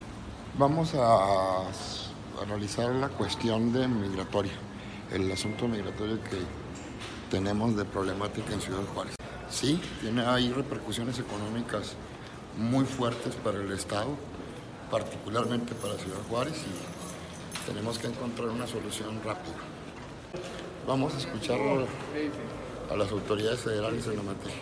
AUDIO: CÉSAR JÁUREGUI MORENO, FISCALÍA GENERAL DEL ESTADO (FGE)